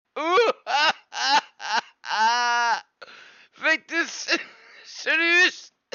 victus-lacht-mp3cut.mp3